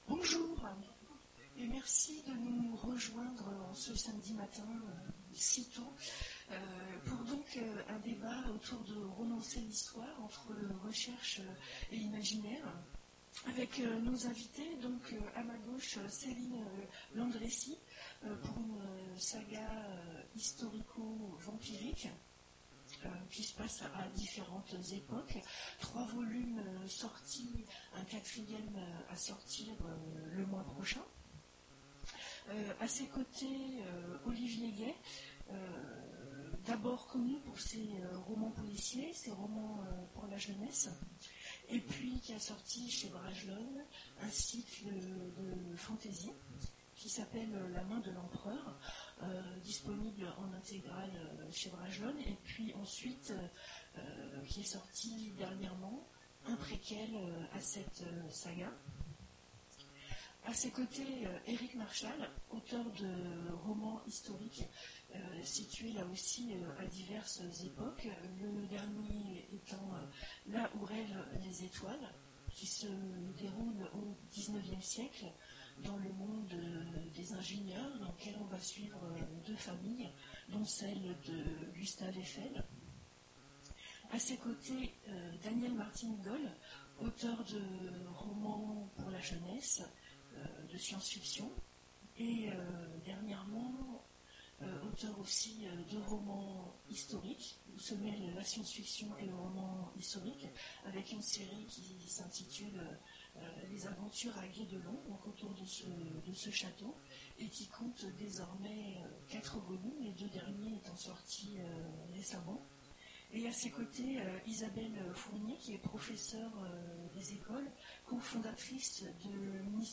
Imaginales 2017 : Conférence Romancer l'histoire : entre recherche et imaginaire ?